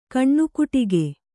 ♪ kaṇṇukuṭige